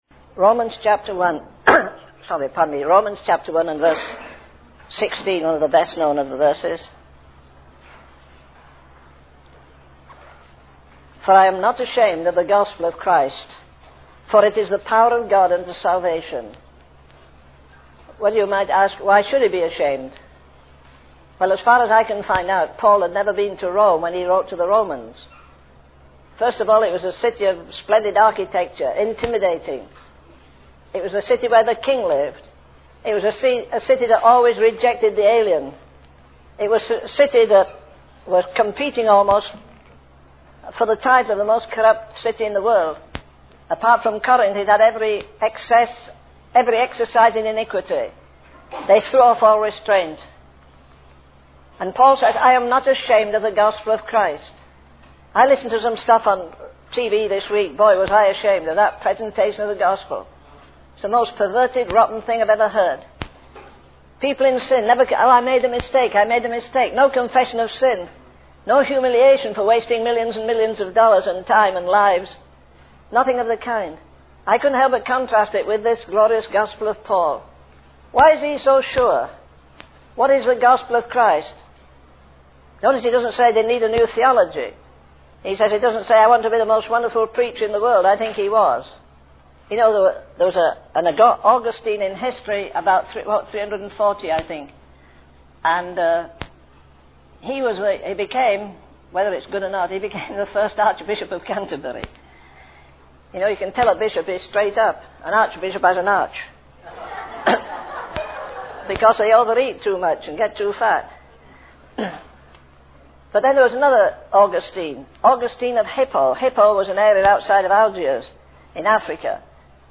In this sermon, the preacher references John Bunyan's depiction of the celestial city and encounters with a lion and demons. He emphasizes not to fear the devil's roar and highlights the futile attempts of demons to extinguish the testimony of individuals and the church.